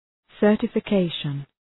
Shkrimi fonetik{,sɜ:rtəfə’keıʃən}
certification.mp3